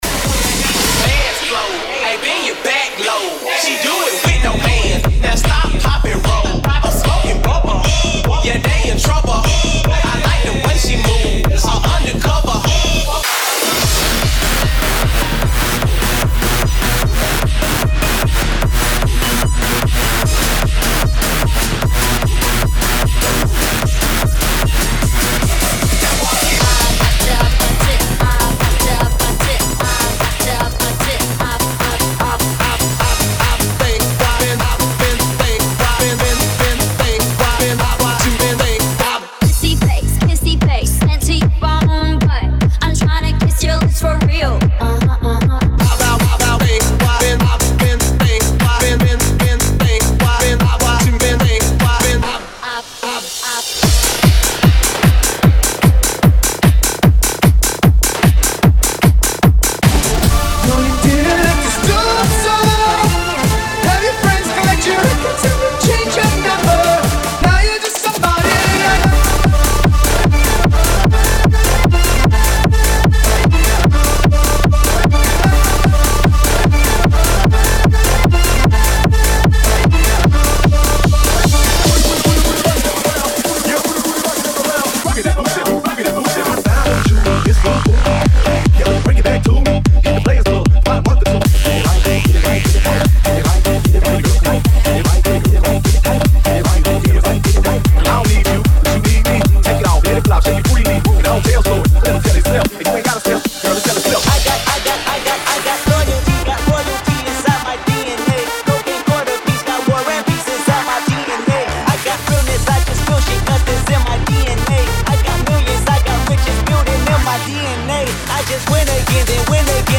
Hard Hittin’ on all levels!